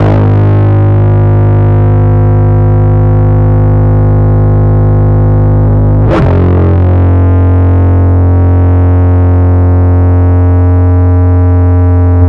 Track 07 - Bass 02.wav